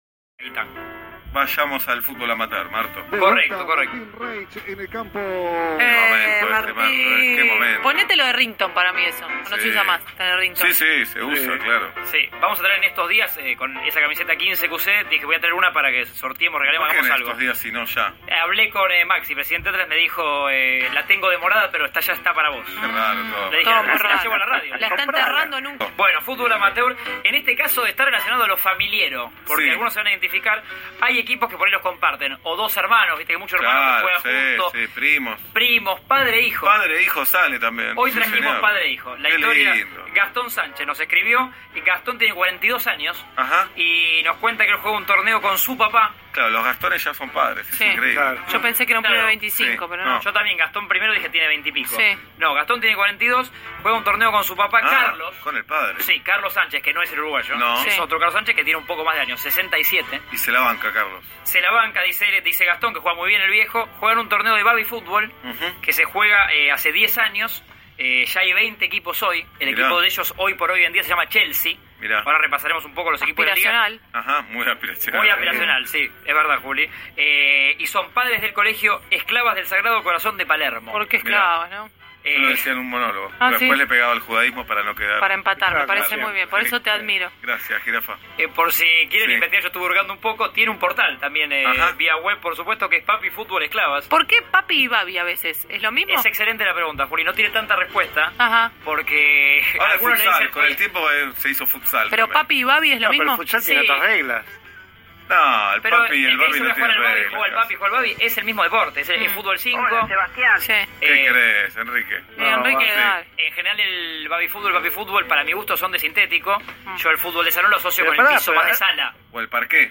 Si, uno de nuestros players no tuvo mejor idea que enviar una carta a la sección «Futbol Amateur» del programa de radio Metro…  y nos pusieron en el aire…